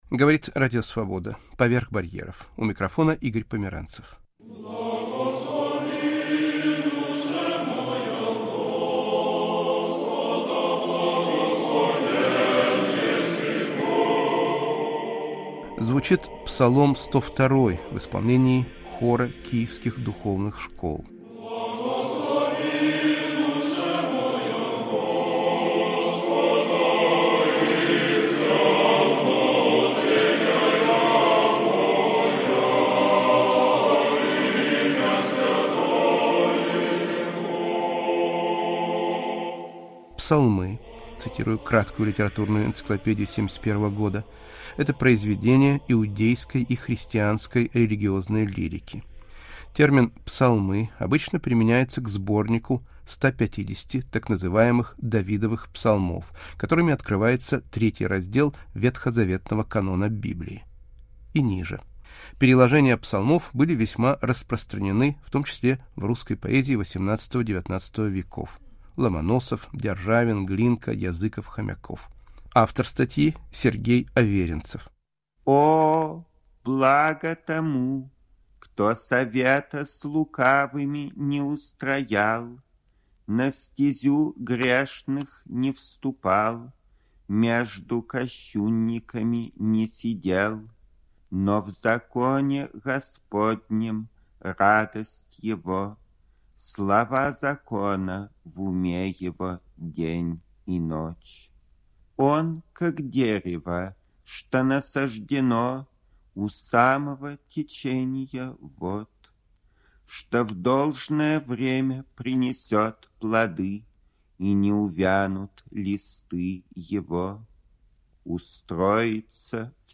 В переводе и авторском чтении Сергея Аверинцева